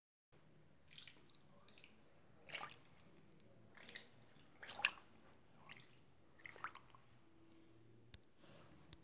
Movimientos en el agua. Chapoteo suave
Grabación sonora del sonido tenue de alguién dándose un baño, o simplemente moviéndose suávemente en el agua.
chapoteo
Sonidos: Agua